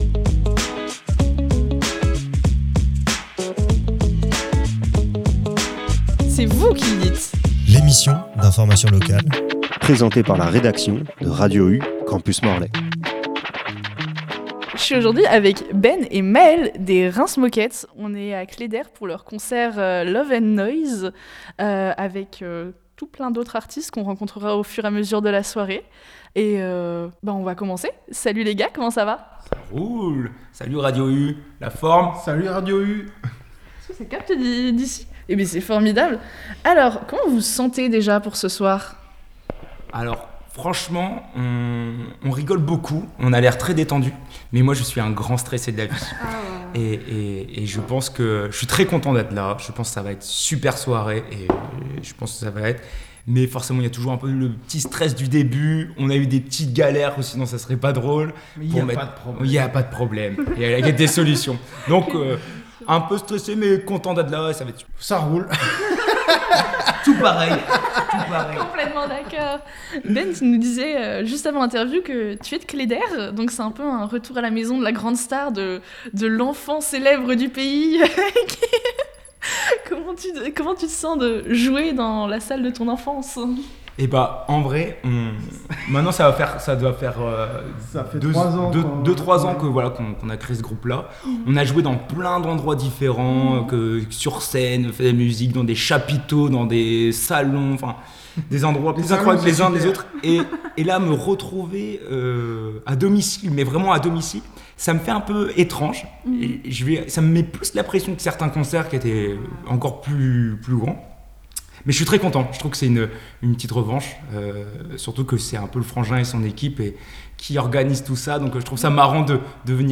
À l’occasion du festival Love and Noise à Cléder le 14 février dernier, nous avons rencontré les groupes qui s’y présentaient dont Rince Moket. Un échange authentique qui permet de découvrir l’envers du décor et de mieux comprendre l’esprit festif et engagé de Rince Moket.